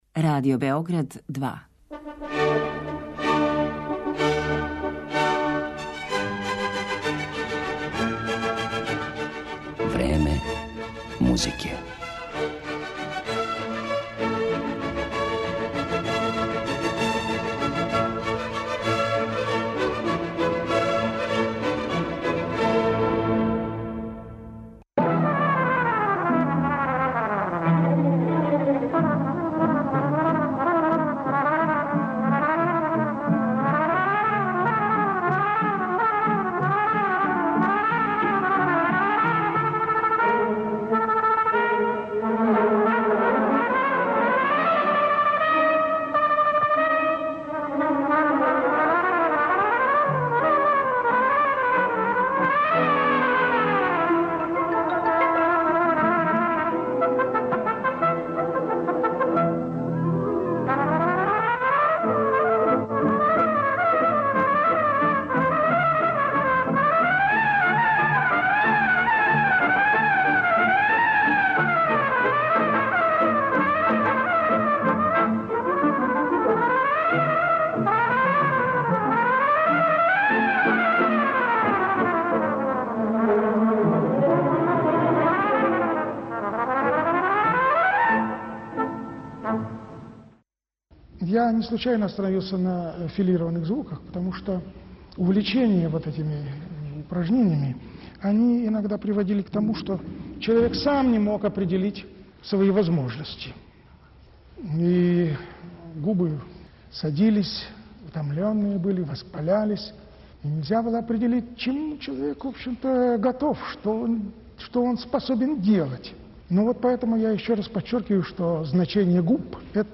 трубу